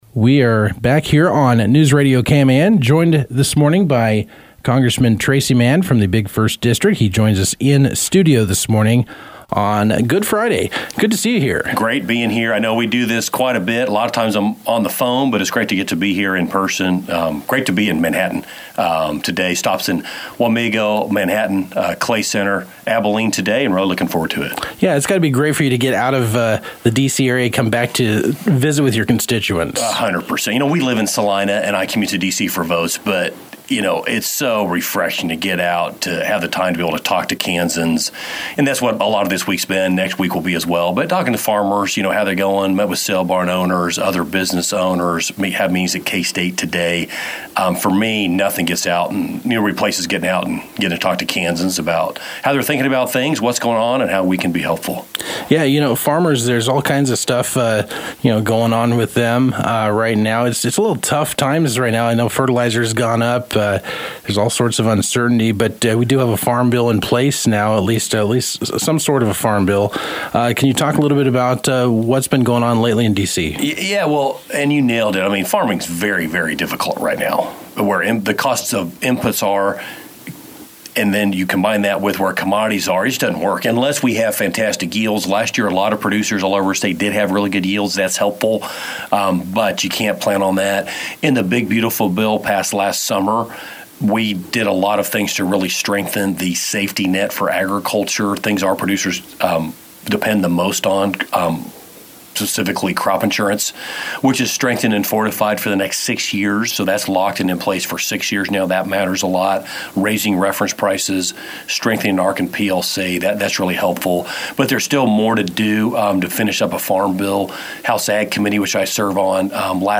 Kansas U.S. Rep. Tracey Mann (R-01) sat down this week to discuss ongoing pressures facing farmers, the conflict in Iran and the continuing partial government shutdown impacting the U.S. Department of Homeland Security.